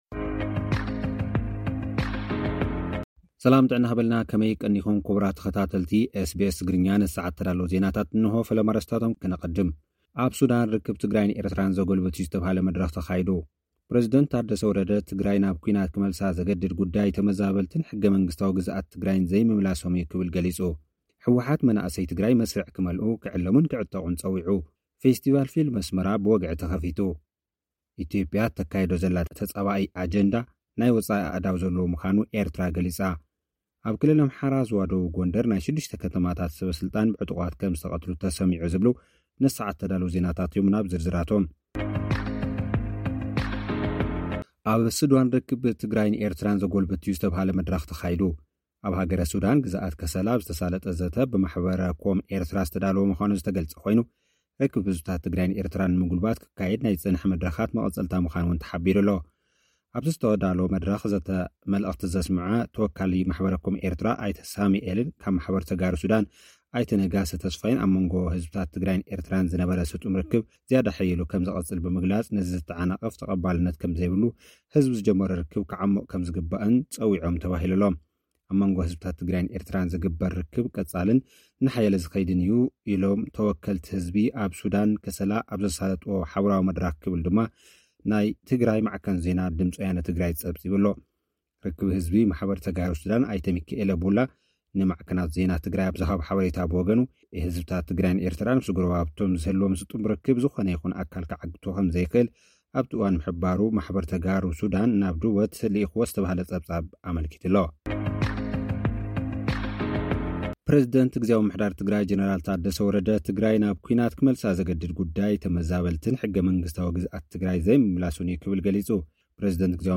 ህወሓት መናእሰይ መስርዕ ክመልኡ፣ ክዕለሙን ክዕጠቑን ፀዊዑ። (ጸብጻብ)